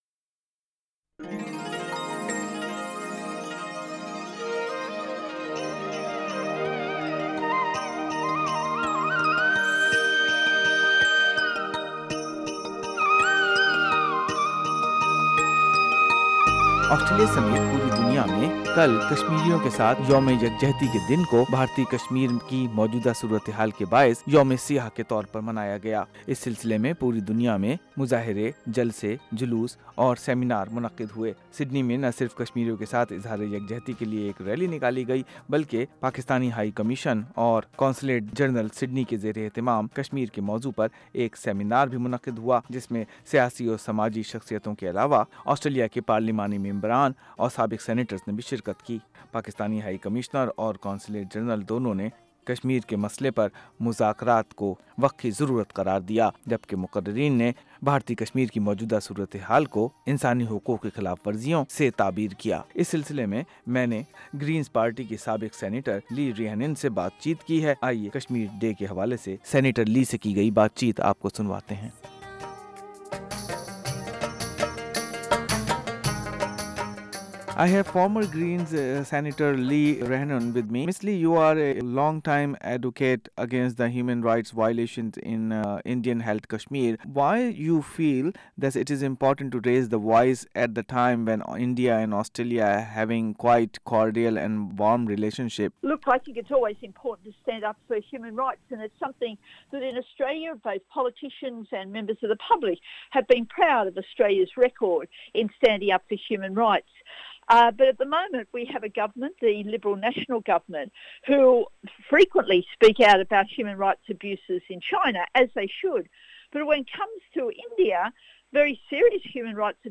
(Right) Consul General in Sydney, Muhammad Ashraf Source: Consulate General of Pakistan in Sydney گرینز پارٹی کی رہنما لی رائنن سے ایس بی ایس اردو نے خصوصی گفتگو کی جسے سننے کے لئے تصویر کے اوپر اسپیکر پر کلک کیجئے۔